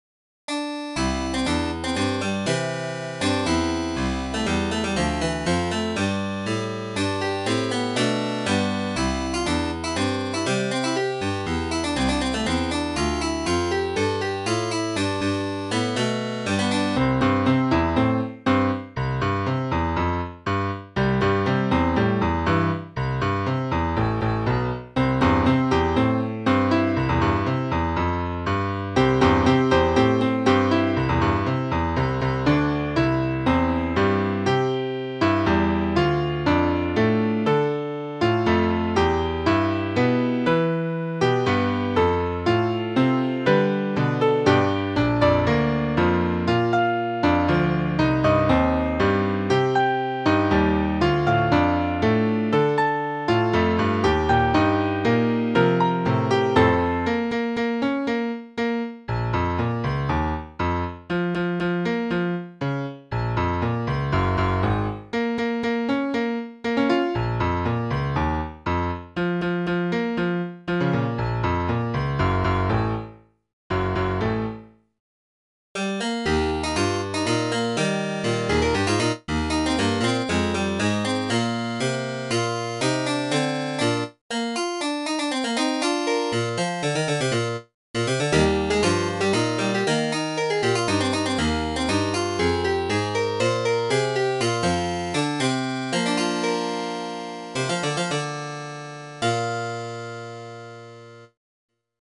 Mostly upbeat piece for piano and harpsichord.